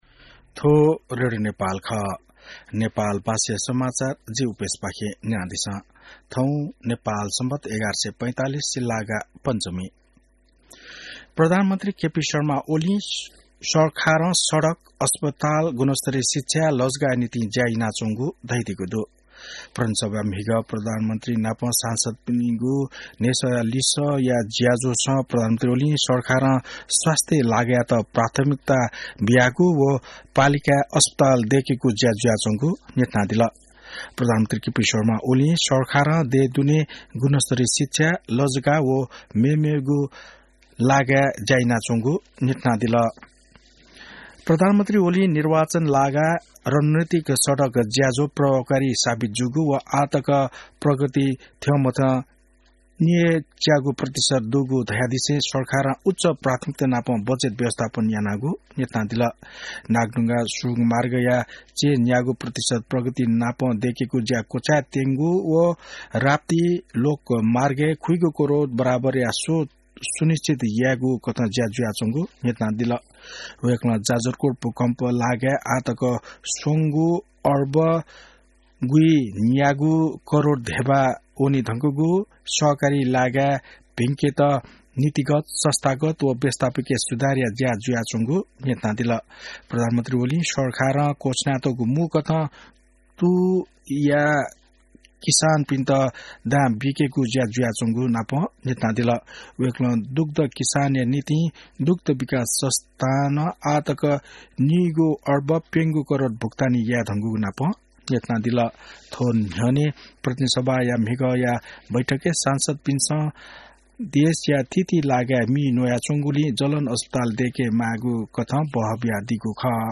नेपाल भाषामा समाचार : ६ फागुन , २०८१